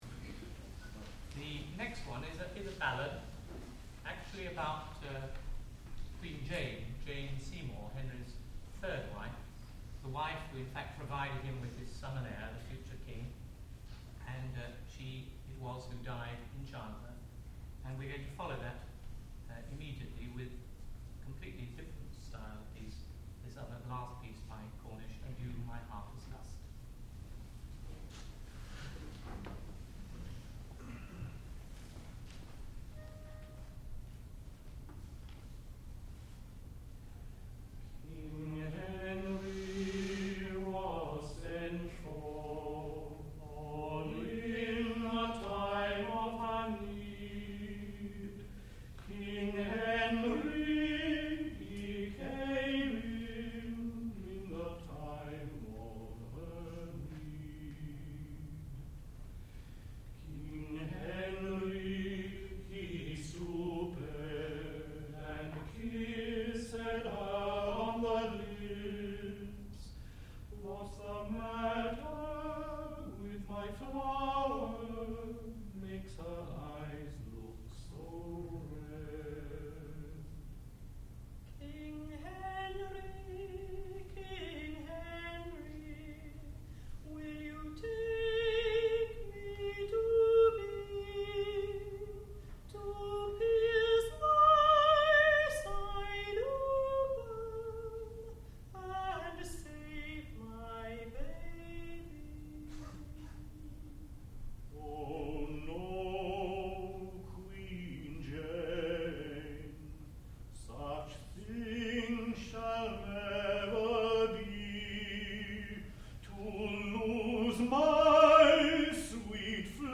sound recording-musical
classical music
tenor
soprano
lute